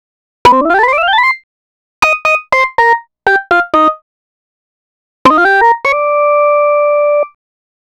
Astro 5 Organ-C.wav